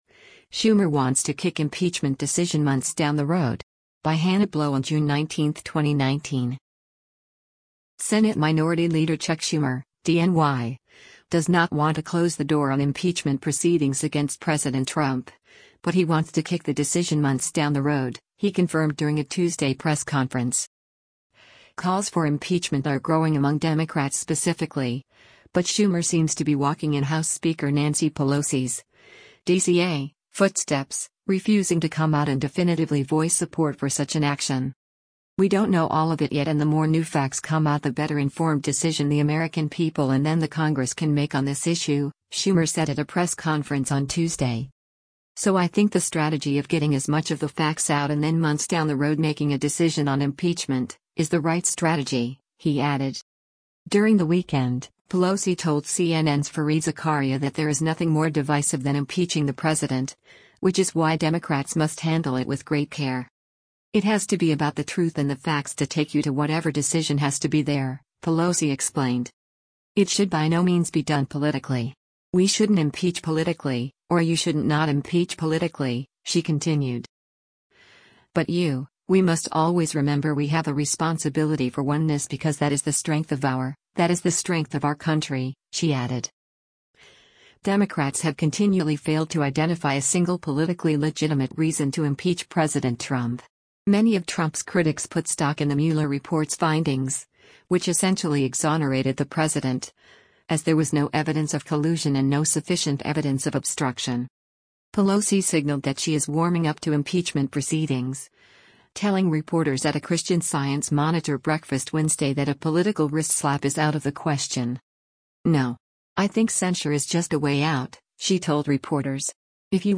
“We don’t know all of it yet and the more new facts come out the better-informed decision the American people and then the Congress can make on this issue,” Schumer said at a press conference on Tuesday.